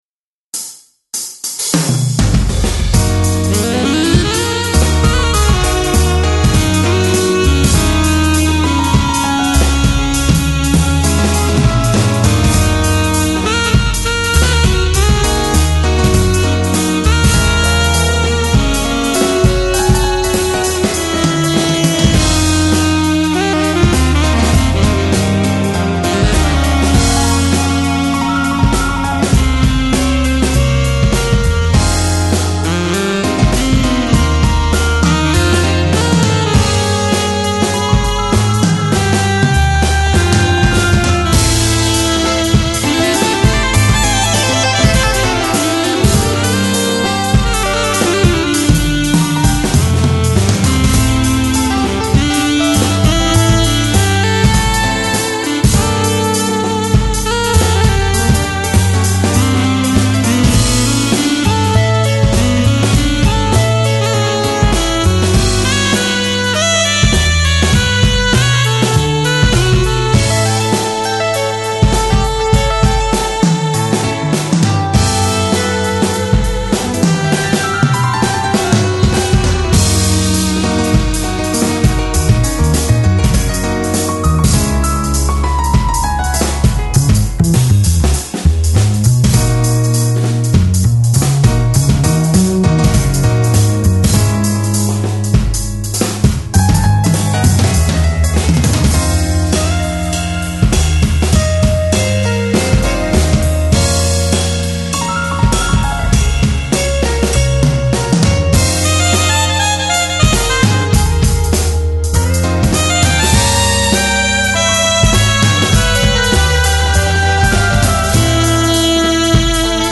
似非ジャズです…すいません。